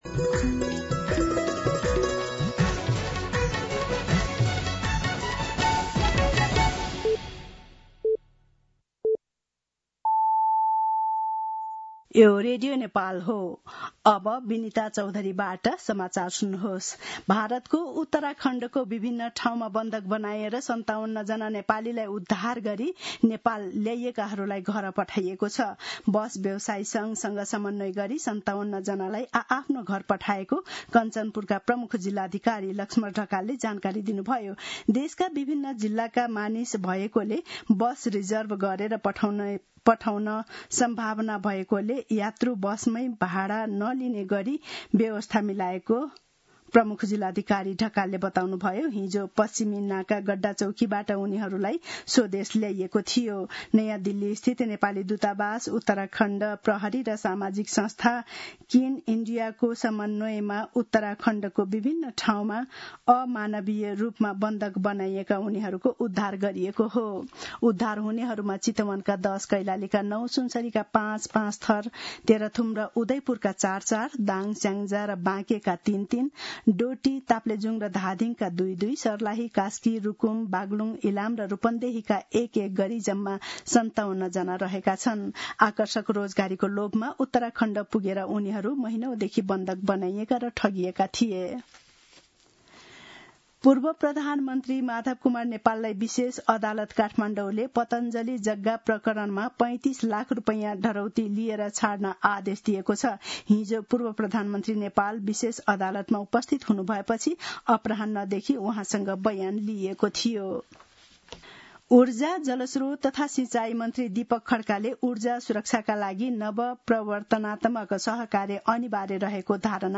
मध्यान्ह १२ बजेको नेपाली समाचार : १२ असार , २०८२